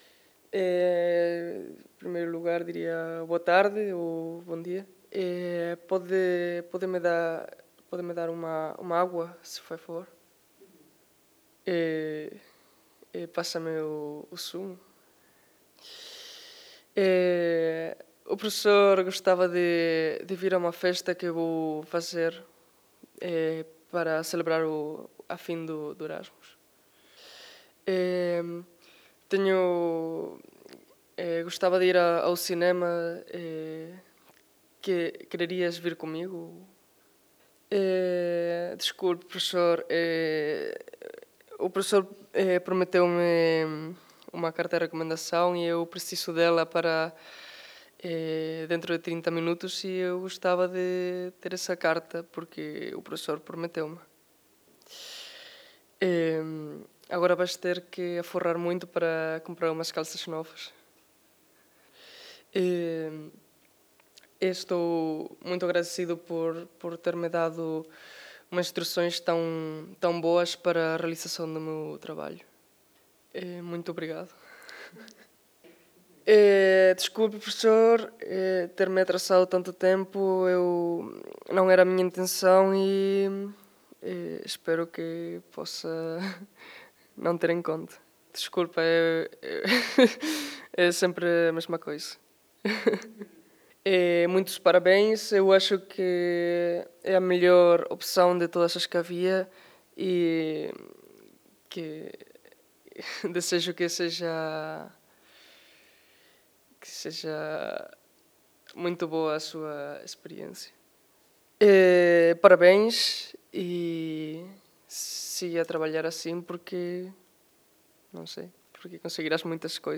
Língua maternaGalego/ Espanhol
QECRLB2